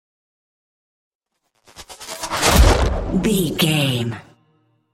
Chopper whoosh to hit engine
Sound Effects
dark
futuristic
intense
tension
woosh to hit